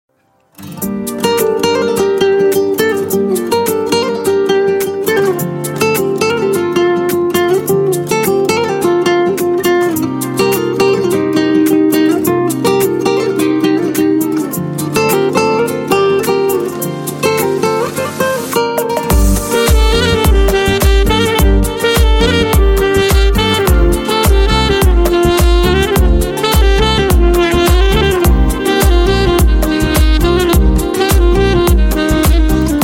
آهنگ زنگ بی کلام